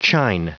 Prononciation du mot chine en anglais (fichier audio)